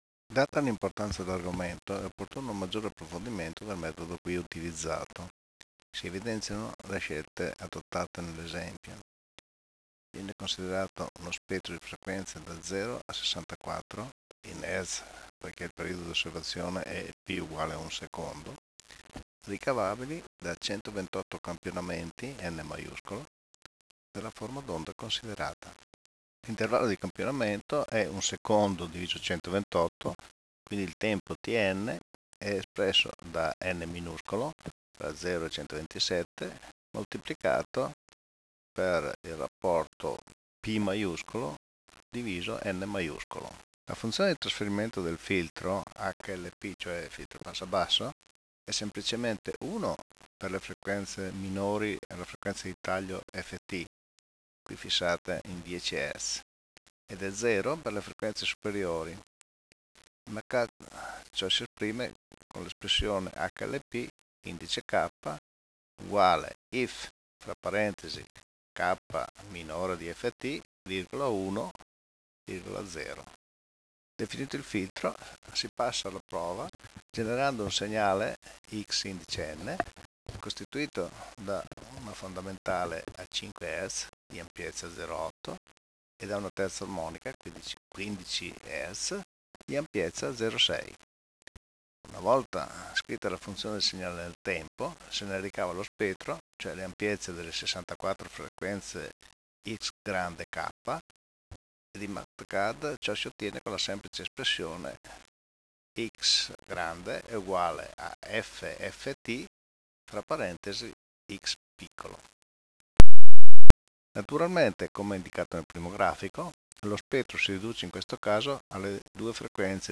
[commento audio]